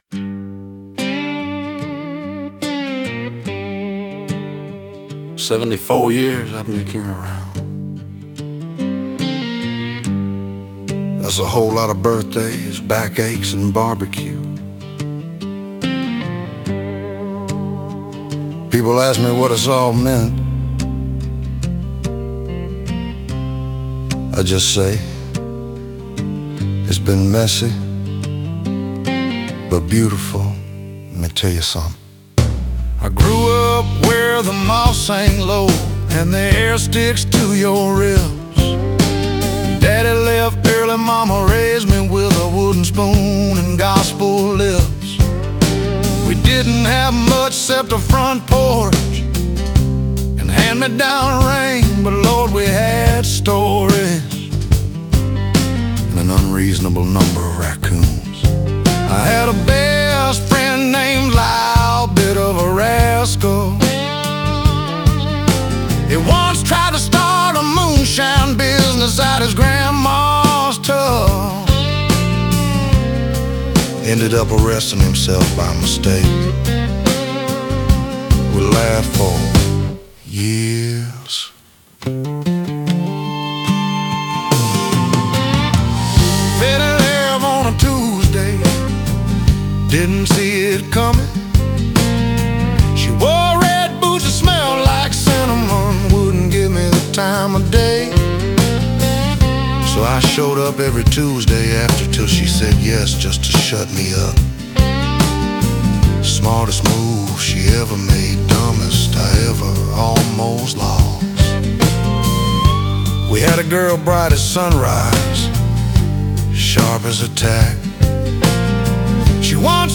Southern Tropic Rock and Blues Music Creation